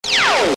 mininglaser.mp3